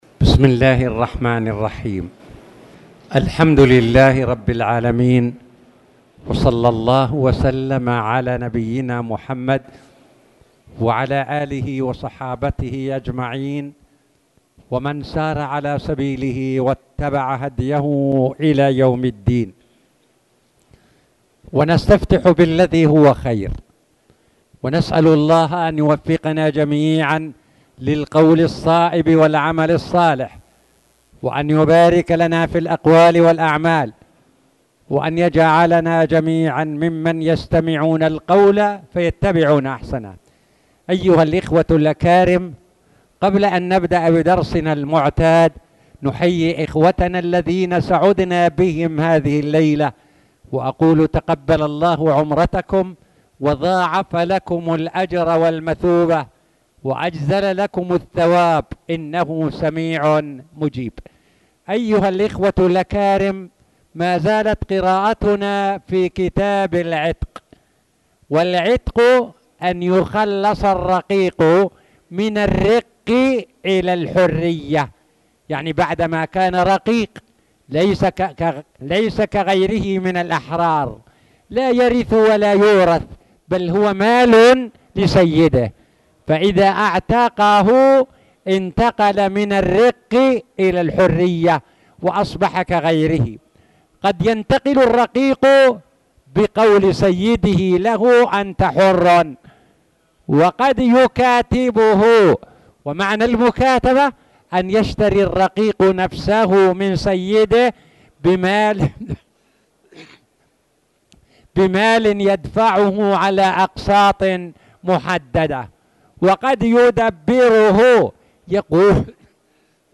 تاريخ النشر ٢٥ محرم ١٤٣٨ هـ المكان: المسجد الحرام الشيخ